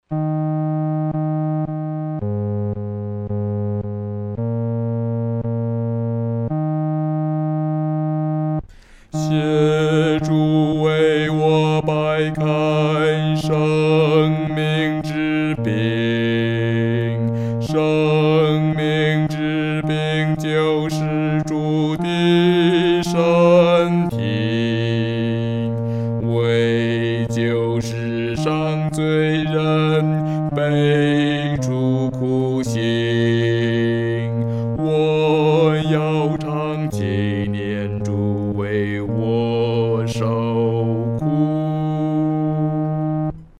独唱（第四声）
求主掰开生命之饼-独唱（第四声）.mp3